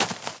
default_grass_footstep.1.ogg